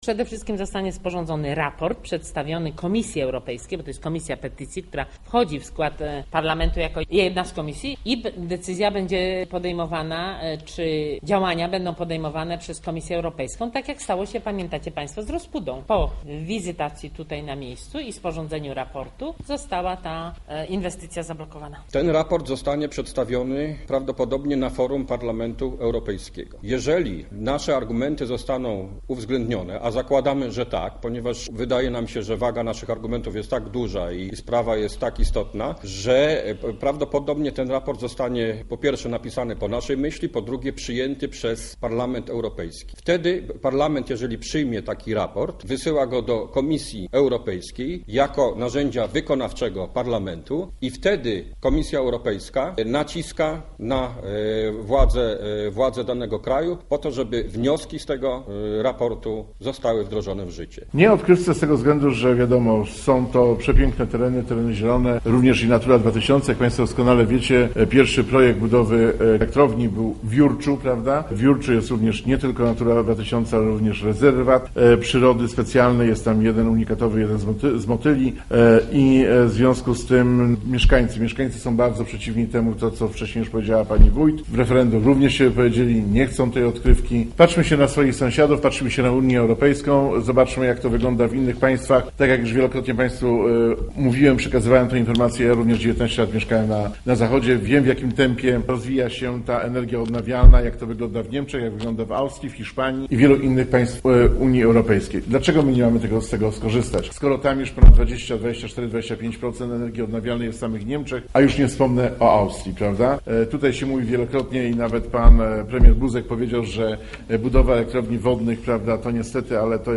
Irena Rogowska, wójt gminy wiejskiej Lubin
burmistrz Ścinawy, Andrzej Holdenmajer